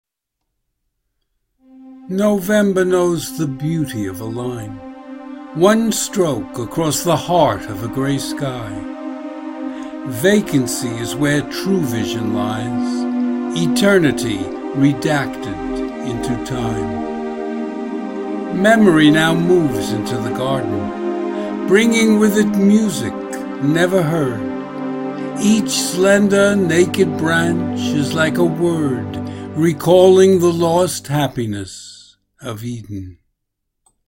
Hear me read the poem as an MP3 file.
Audio and Video Music: Solo Cello Passion.